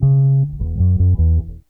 BASS 31.wav